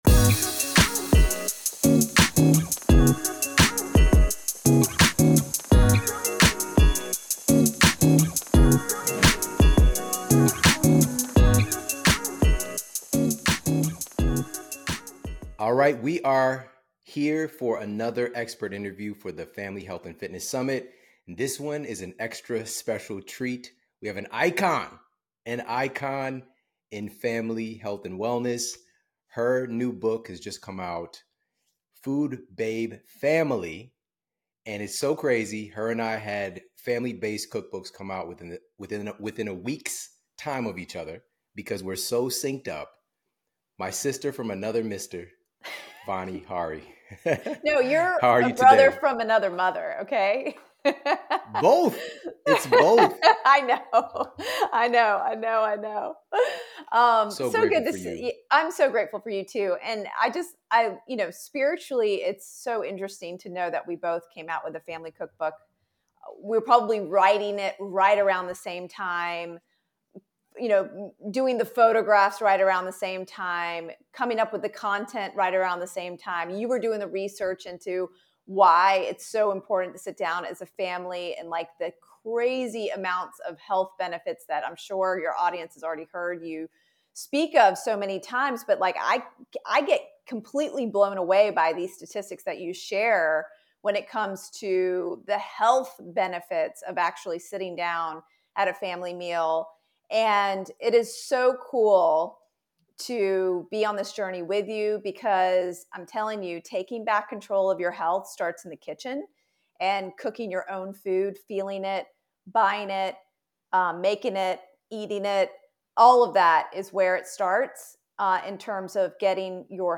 VaniHariSummitInterviewAudio.mp3